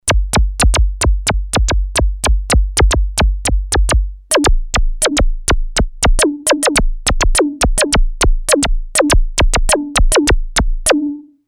edit VOICE analog monophonic module based on subtractive synthesis.
lead